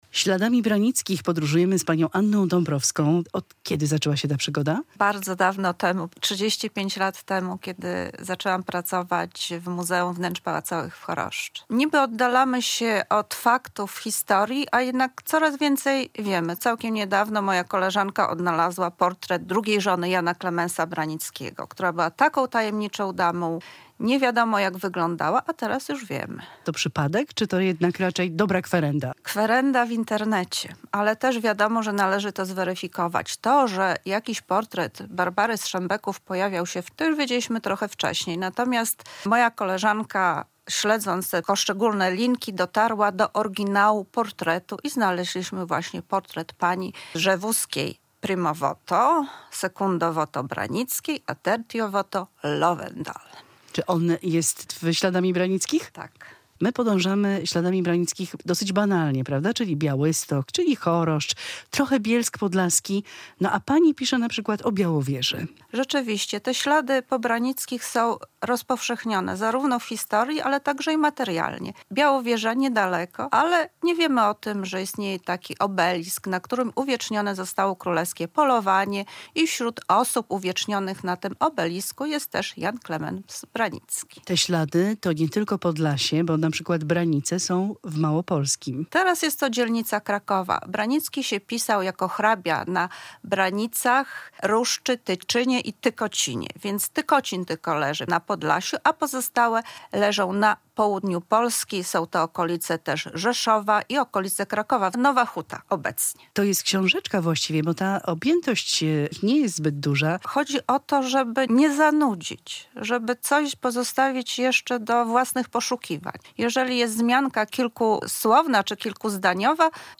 Prowadzący: